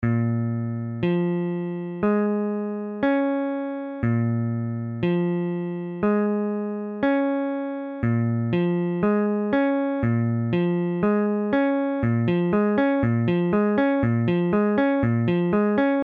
I_Bbm7.mp3